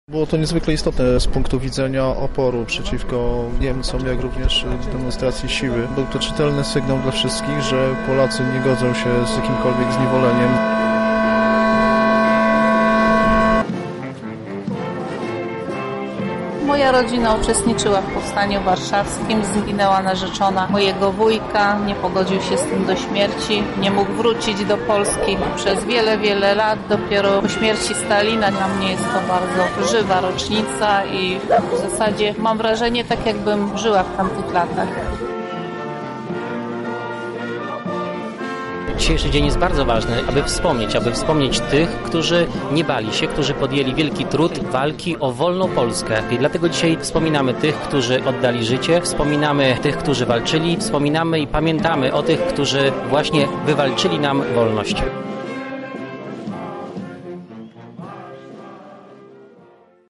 Obchodom rocznicy przyglądał się nasz reporter: